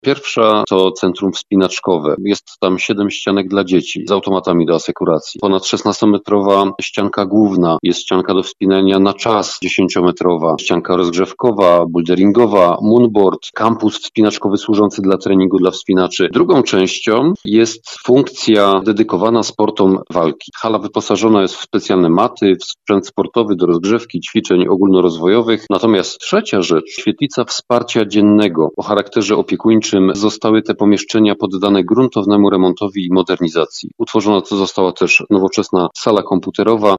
- To jeden z najnowocześniejszych obiektów na Śląsku. Inwestycja składa się z trzech części - mówi Krystian Grzesica, burmistrz Bierunia.